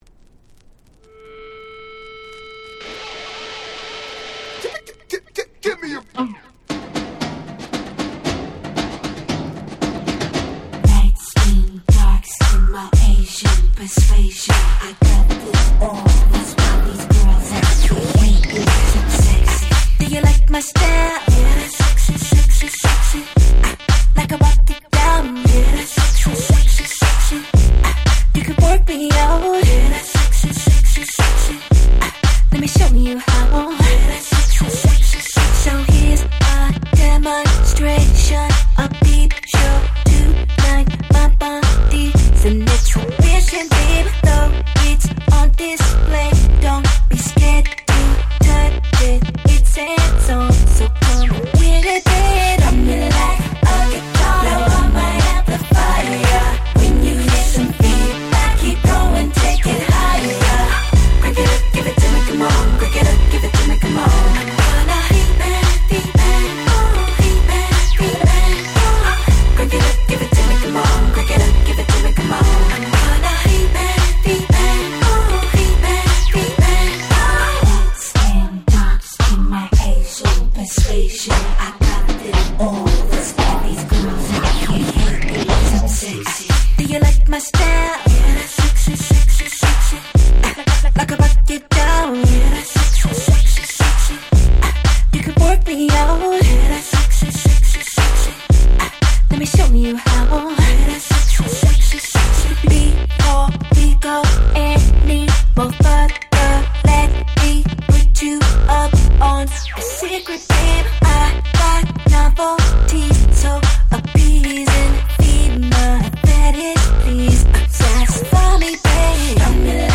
08' Smash Hit R&B !!